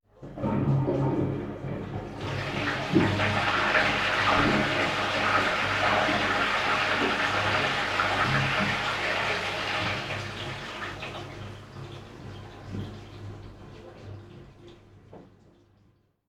Sonido del baño de los vecinos
ducha
Sonidos: Agua
Sonidos: Hogar